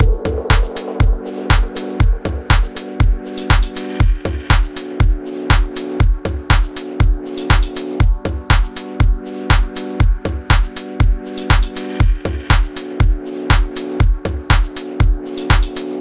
Deep House Drums
Я сделал драм секцию, пытаюсь приблизится к буржуям, но не особо получается.